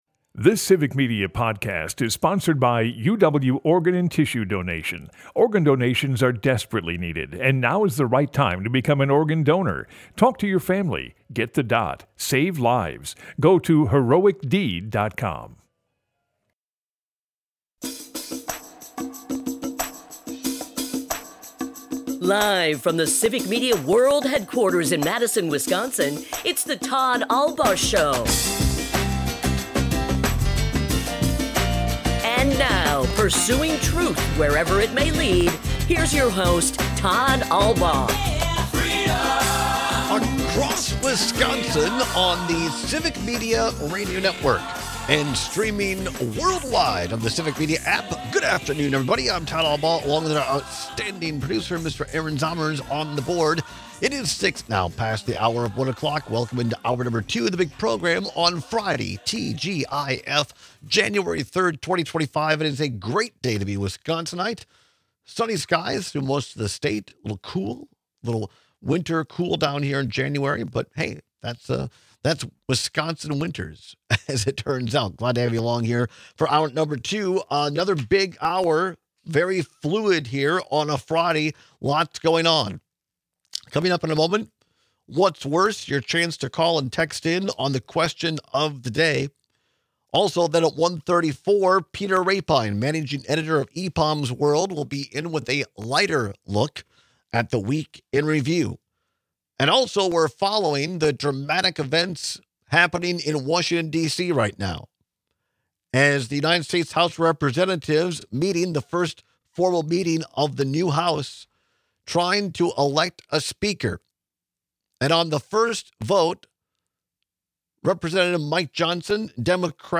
We take your calls and comments, and we come to a consensus.